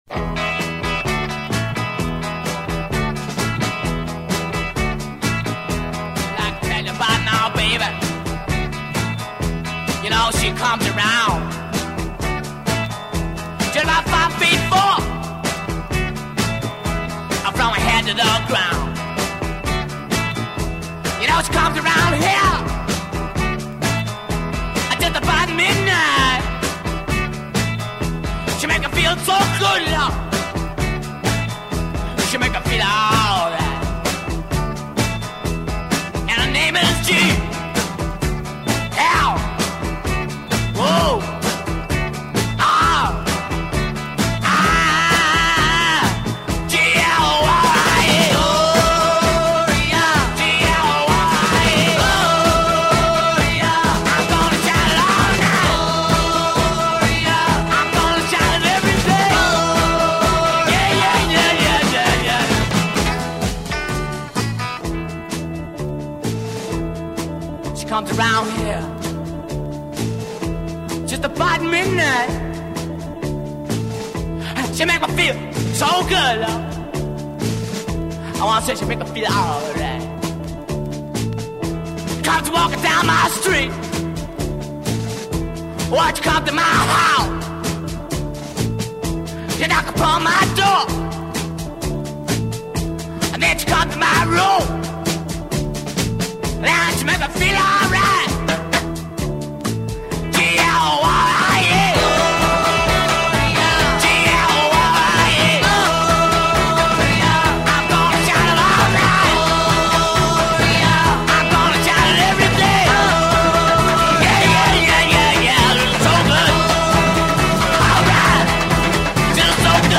lead singer and saxophonist
recorded seven songs in one session
a young session guitarist
has a fairly repetitive three-chord riff
half speak and half sing